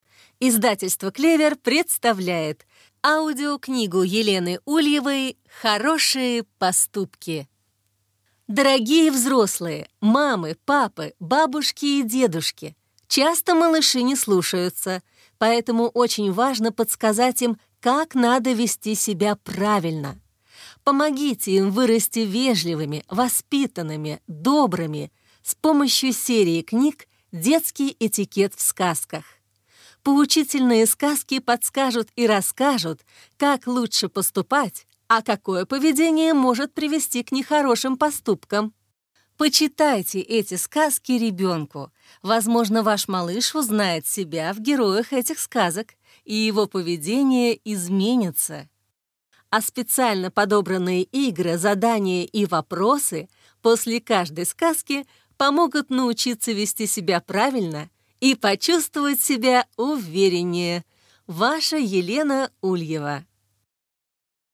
Аудиокнига Хорошие поступки | Библиотека аудиокниг